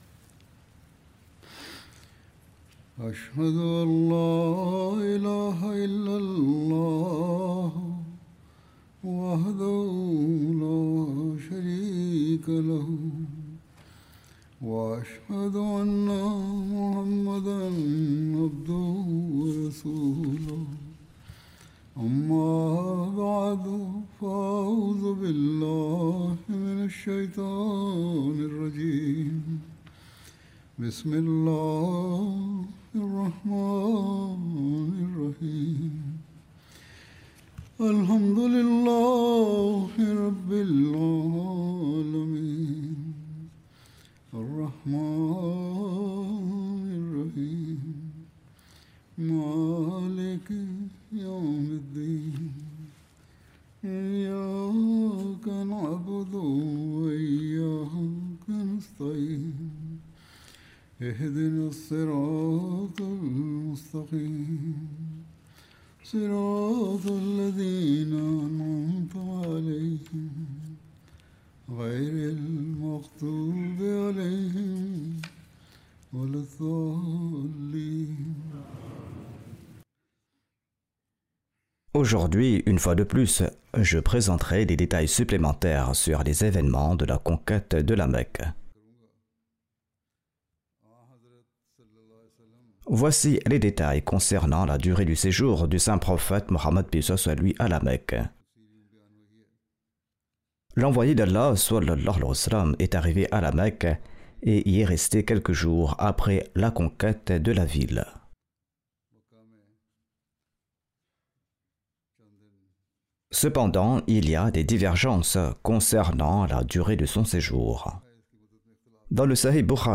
French Friday Sermon by Head of Ahmadiyya Muslim Community
French Translation of Friday Sermon delivered by Khalifatul Masih